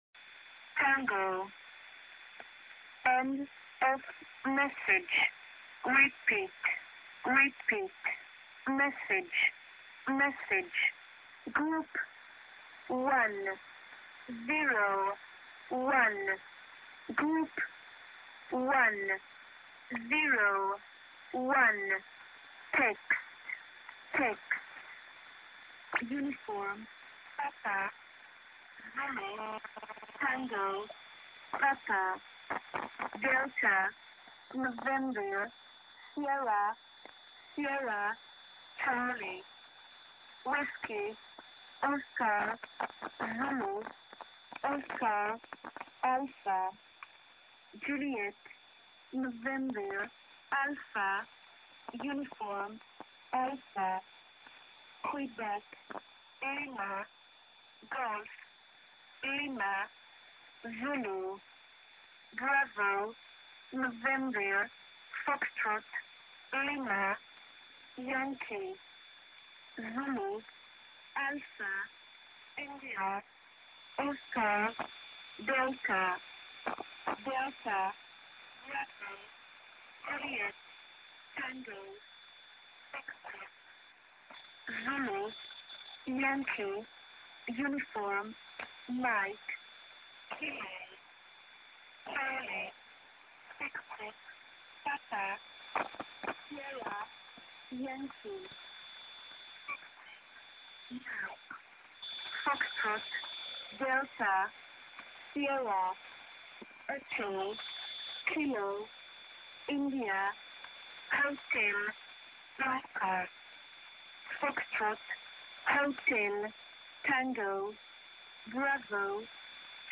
6843 кГц, номерная станция
Качественная запись!
Спасибо. принимал Деген 1103 , писал через диктофон - motorola rizr-z3 , конвертация - imtoo3gp